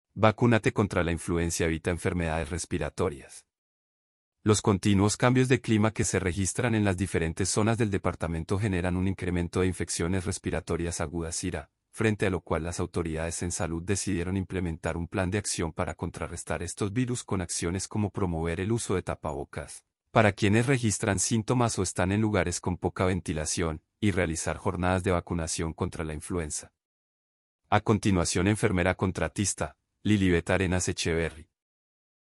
Audio Noticia – Territorial de Salud de Caldas